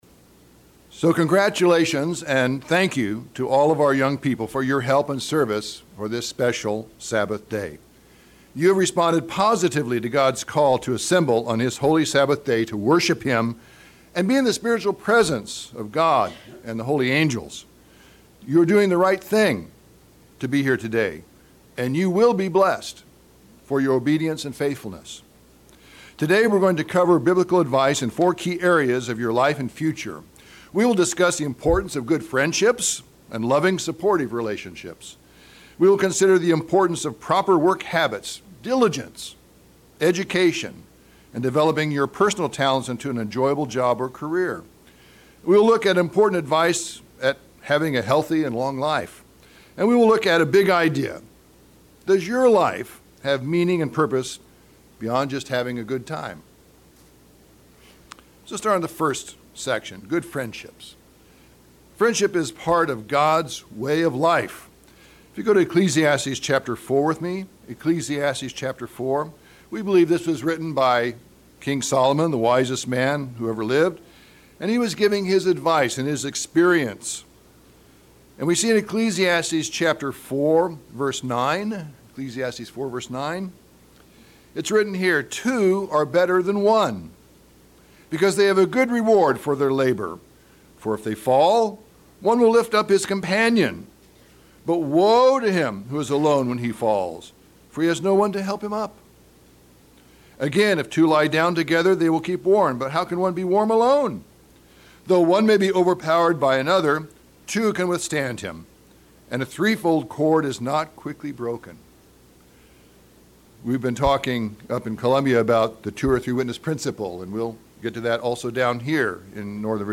Explain biblical principles of life and success mainly to our young people, as this is their special youth sabbath service day.
Sermons
Given in Northern Virginia